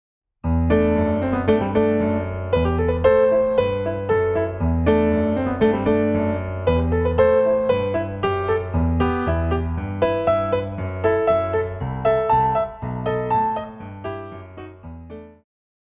古典,流行
鋼琴
經典曲目,古典音樂
演奏曲
世界音樂
僅伴奏
沒有主奏
沒有節拍器